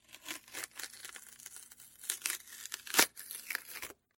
6. Что-то липкое отклеивают